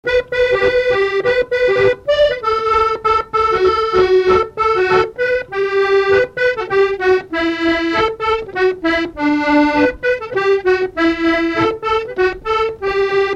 Thème : 1074 - Chants brefs - A danser
Résumé instrumental
danse : scottish (autres)
Catégorie Pièce musicale inédite